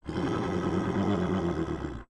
SFX_Wolf_Growl_02.wav